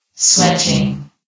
sound / vox_fem / sweating.ogg
CitadelStationBot df15bbe0f0 [MIRROR] New & Fixed AI VOX Sound Files ( #6003 ) ...
sweating.ogg